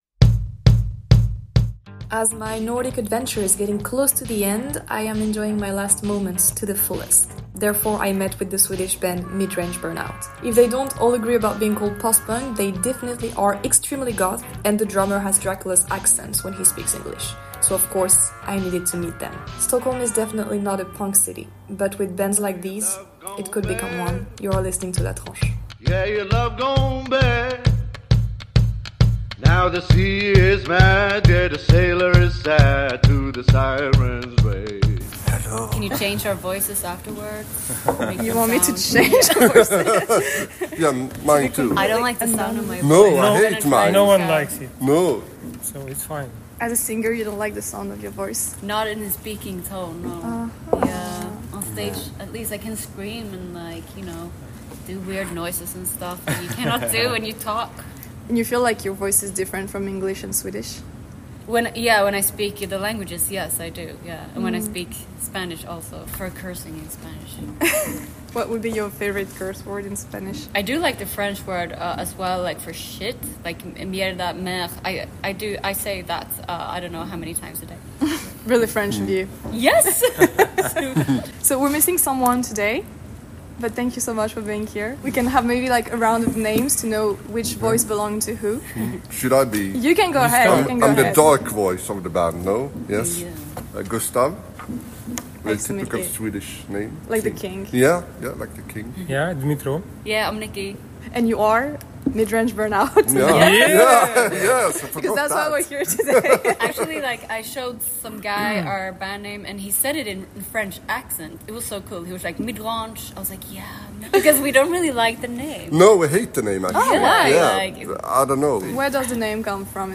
LA TRANCHE - Midrange Burnout [Original English Version] Partager Type Entretien Pop & Rock mardi 28 octobre 2025 Lire Pause Télécharger Pour la version doublée en français : juste ici .